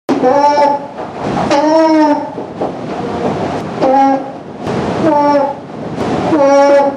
Common Alpaca Vocalizations
Hum From a Concerned Alpaca
Humm-From-A-Concerned-Alpaca.mp3